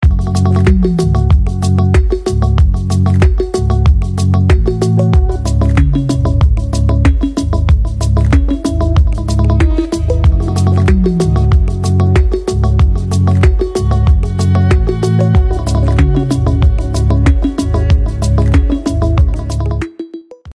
мелодичные
без слов
инструментал
ритмичные